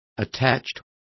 Complete with pronunciation of the translation of attached.